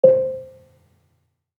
Gambang-C4-f.wav